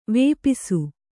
♪ vēpisu